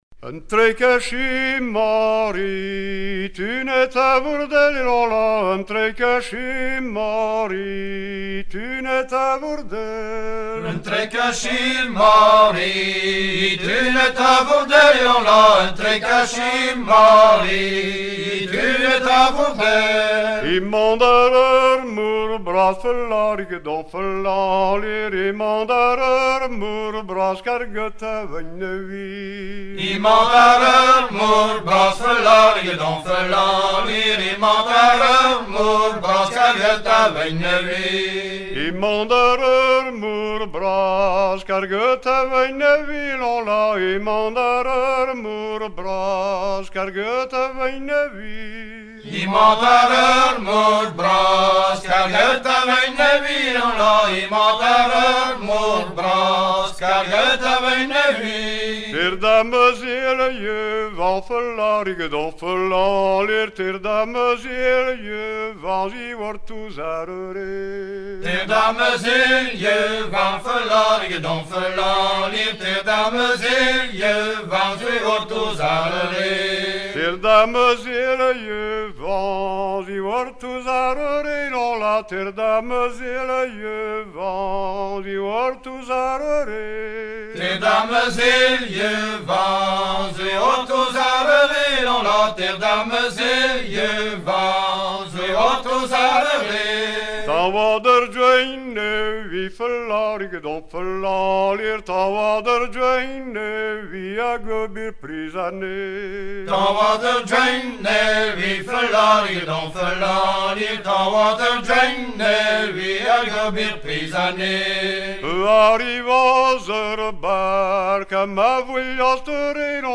Fonction d'après l'analyste gestuel : à marcher
Genre laisse
Pièce musicale éditée